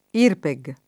vai all'elenco alfabetico delle voci ingrandisci il carattere 100% rimpicciolisci il carattere stampa invia tramite posta elettronica codividi su Facebook IRPEG [ & rpe g ] s. f. — sigla di Imposta sul Reddito delle Persone Giuridiche